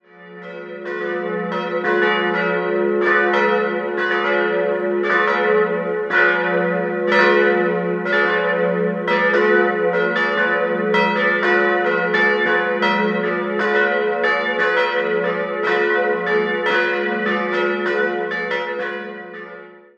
In den Jahren 1712/13 erfolgte ein barocker Neubau nach den Plänen des berühmten Eichstätter Hofbaumeisters Gabriel de Gabrieli. 3-stimmiges TeDeum-Geläute: fis'-a'-h' Die Glocken wurden 1950 von Karl Czudnochowsky in Erding gegossen.